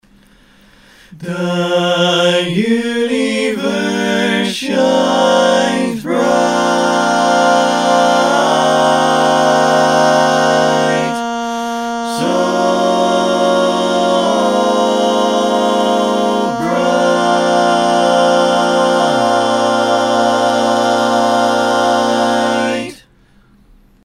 Key written in: B♭ Major
How many parts: 5
Type: Barbershop
All Parts mix: